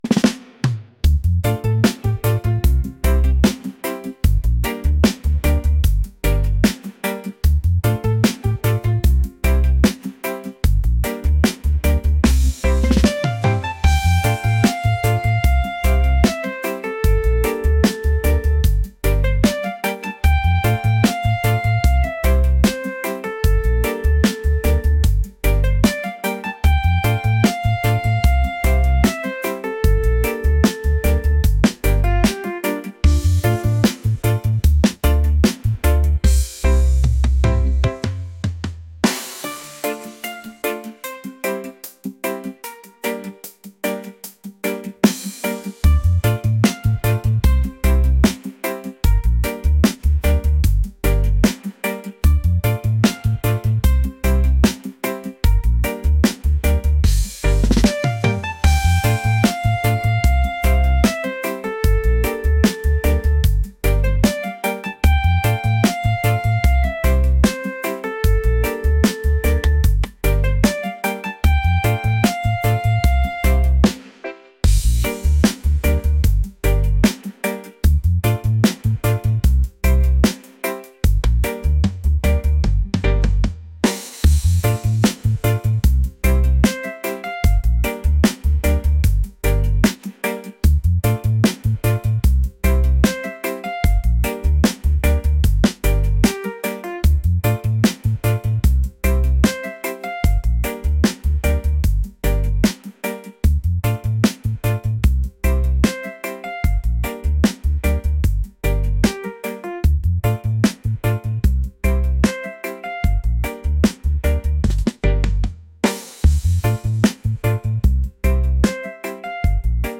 reggae | relaxed | smooth